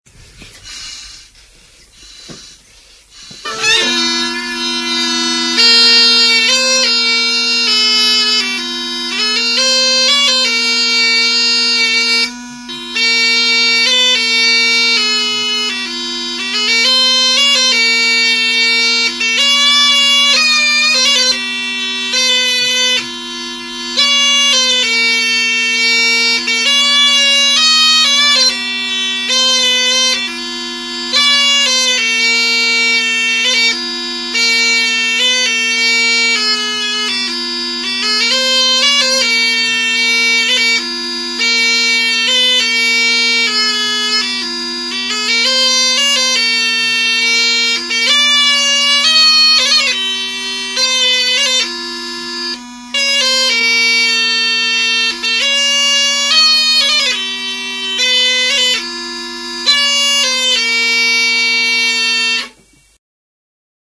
Torupillilugu 2